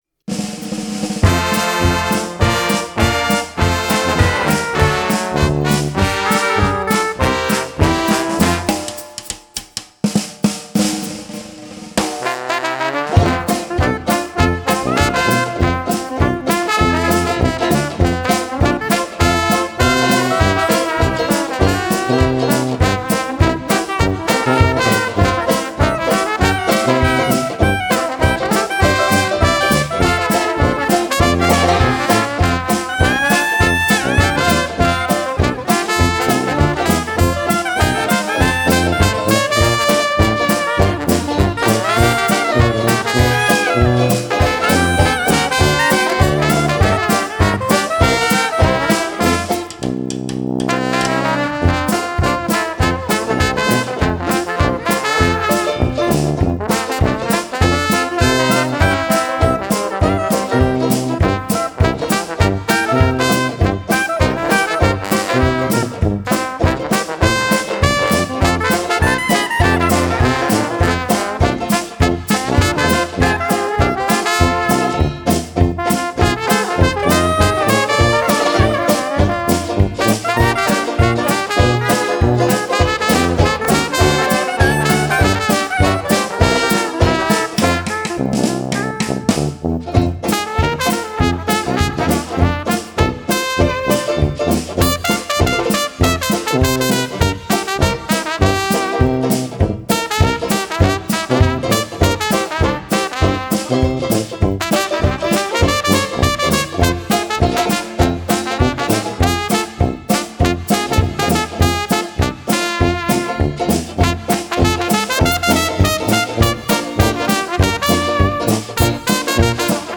Suonare il sax è la mia passione!
Sax
LE MIE PERFORMANCES